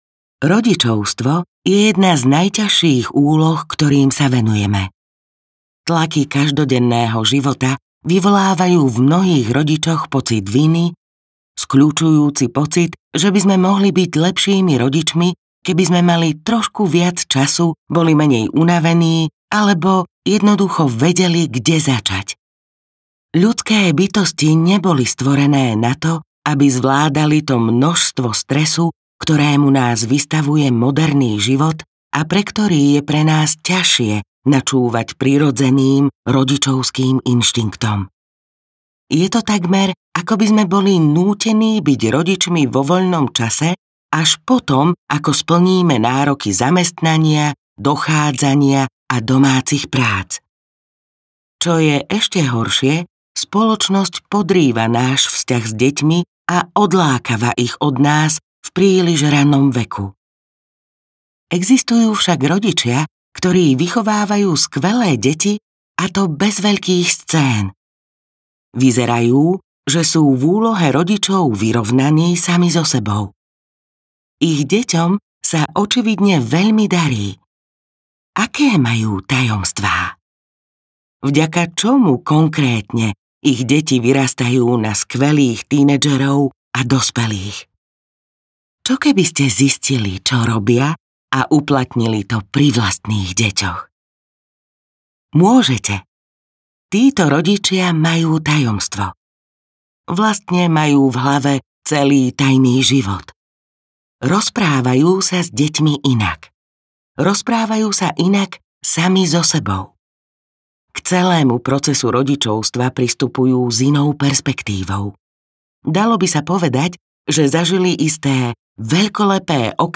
AHA! Rodičovstvo audiokniha
Ukázka z knihy